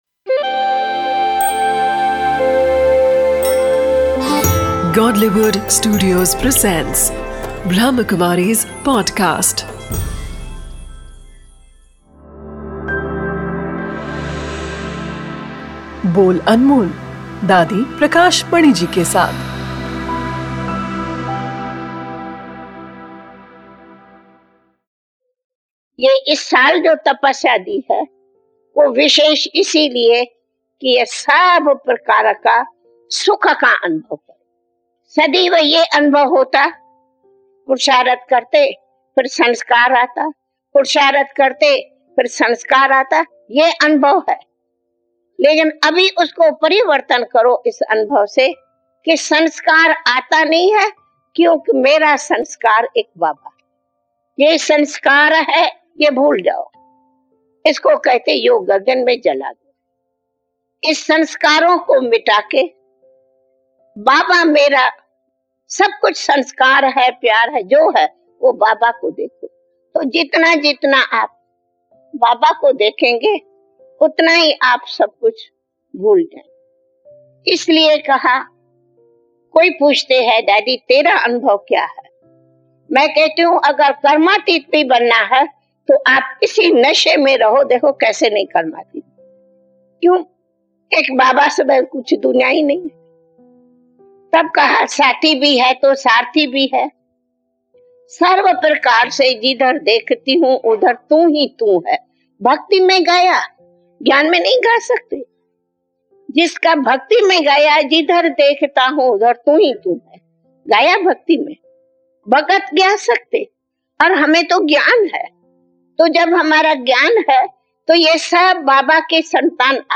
a series of lectures brimming with spiritual essence